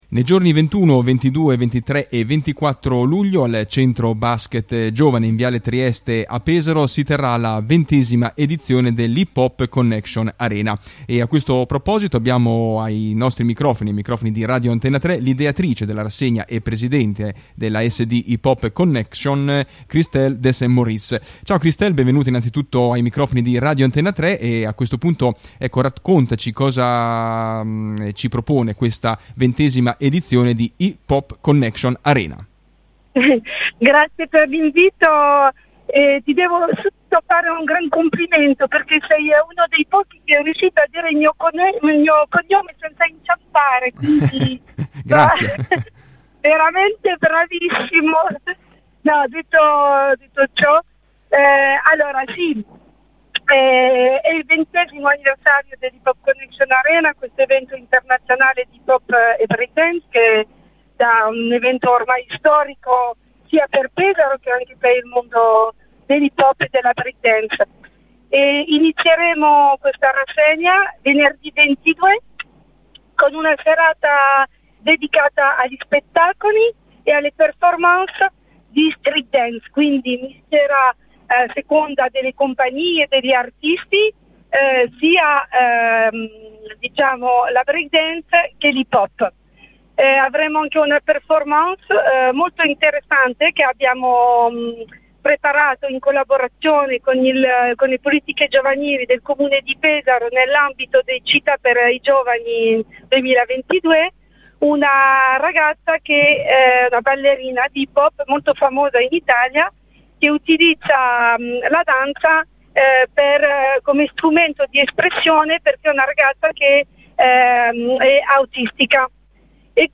Ritorna Hip Hop Connection Arena, dal 21 al 24 Luglio 2022 al Centro Basket Giovane in Viale Trieste a Pesaro. Quella di quest’anno è una data importnate, è infatti, la 20esima Edizione di un Festival nato a Pesaro. Ai nostri microfoni, ce ne parla: